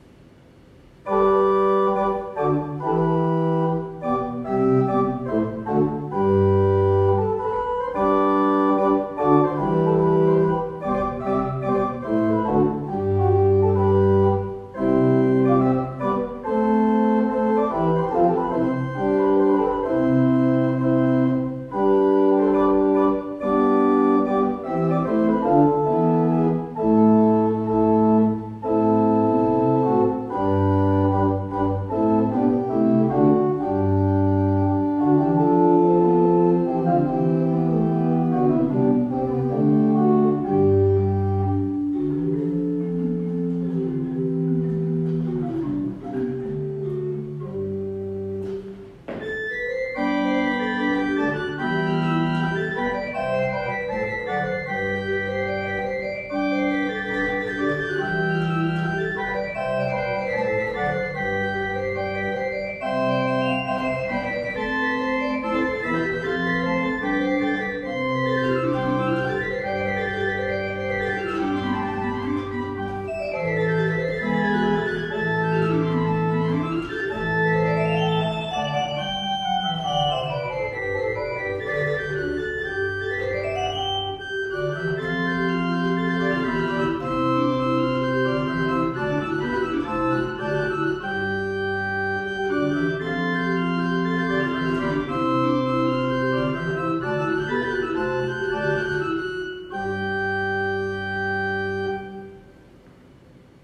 Das Instrument befindet sich in gutem Zustand, ist recht mild intoniert und daher für kleine Kirchenräume oder als Haus- und Übeorgel gut verwendbar.